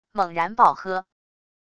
猛然爆喝wav音频